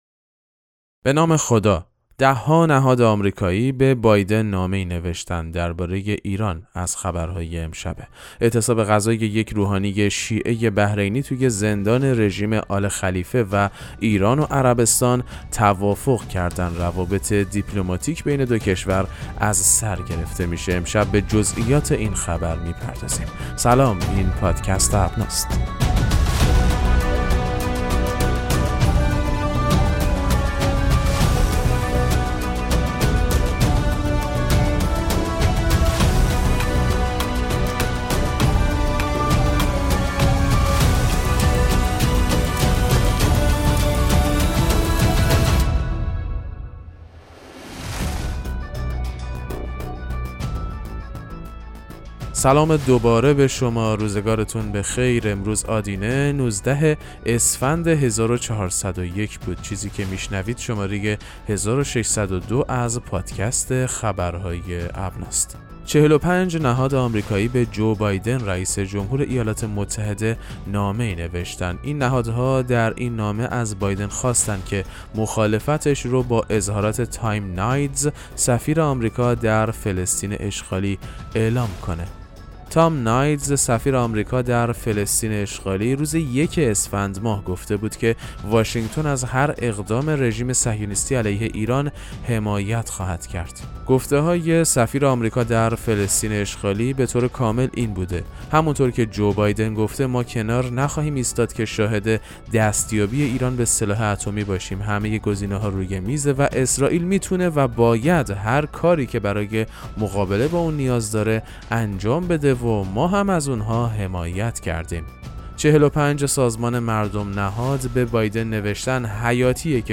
پادکست مهم‌ترین اخبار ابنا فارسی ــ 19 اسفند 1401